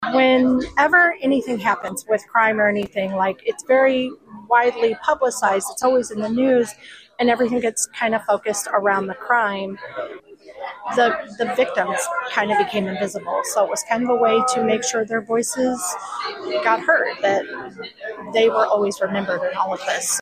On Thursday, the Eastern Reception, Diagnostic and Correctional Center in Bonne Terre held a special ceremony to honor victims of crime.